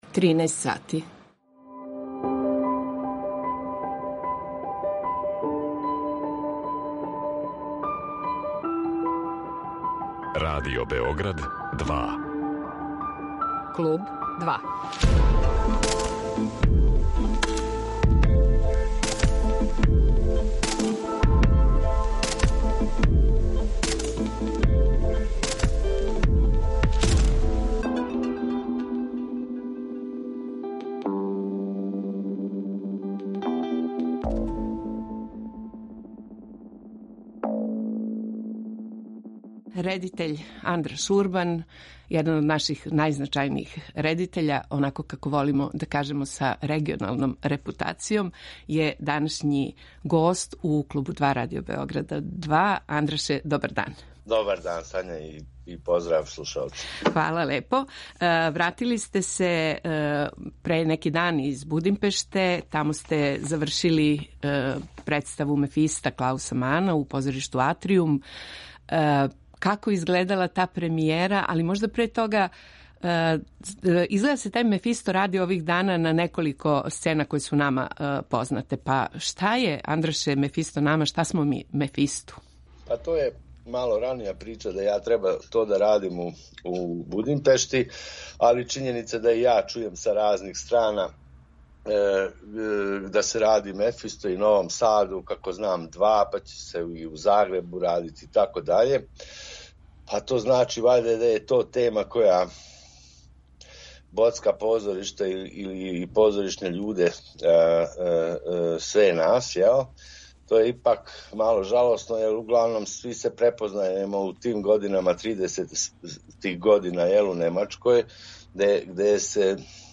Андраш Урбан, један од наших најзначајнијих редитеља, гост је Клуба 2.